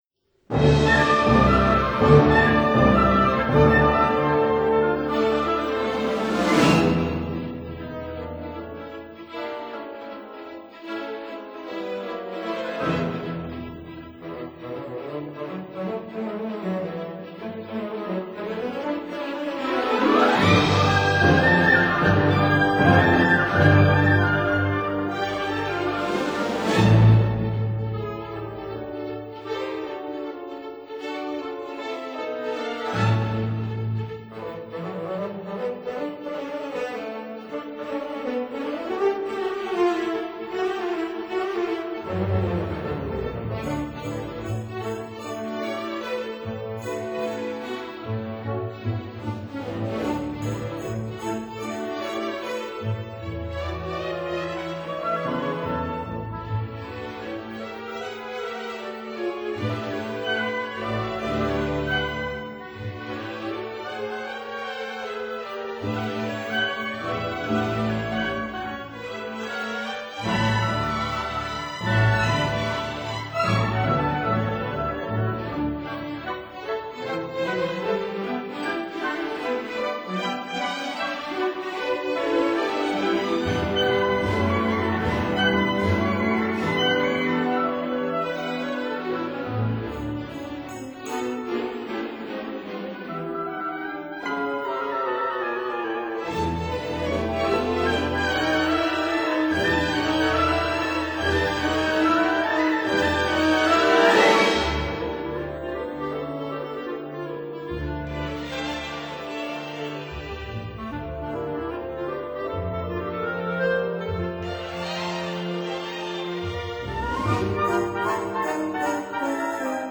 clarinet
trombone